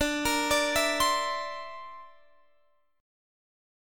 Listen to D7sus2#5 strummed